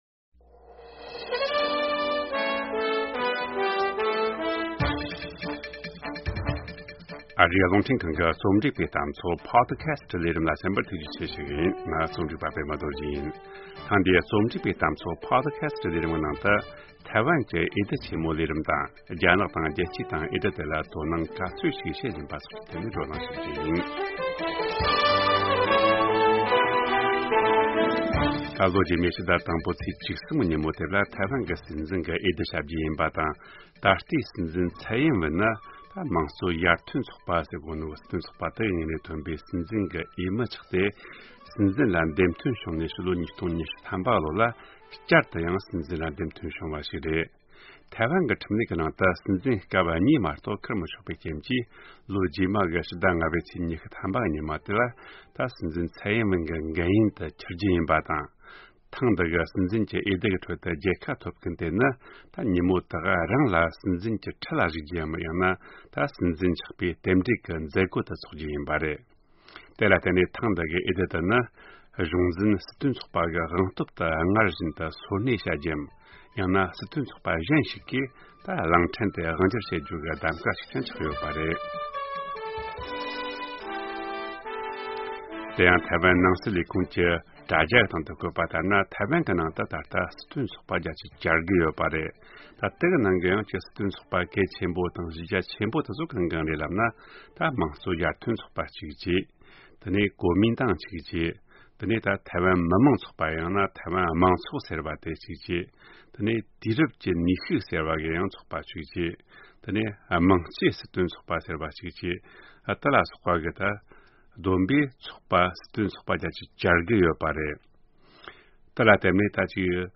ཐེངས་འདིའི་རྩོམ་སྒྲིག་པའི་གཏམ་ཚོགས་པོ་ཊི་ཁ་སི་ལས་རིམ་ནང་། ཐེ་ཝན་གྱི་༢༠༢༤ ལོའི་སྲིད་འཛིན་འོས་བསྡུའི་ནང་མཉམ་ཞུགས་གནང་མཁན་གྱི་འོས་མི་དང་། རྒྱ་ནག་དང་རྒྱལ་སྤྱིའི་སྟེང་འོས་བསྡུ་དེར་དོ་སྣང་ག་ཚོད་བྱེད་བཞིན་པ་དང་དོ་སྣང་བྱེད་དགོས་པའི་རྒྱུ་མཚན་སོགས་ཀྱི་ཐད་བགྲོ་གླེང་བྱས་ཡོད།